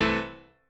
admin-leaf-alice-in-misanthrope/piano34_1_002.ogg at main